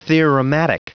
Prononciation du mot theorematic en anglais (fichier audio)
Prononciation du mot : theorematic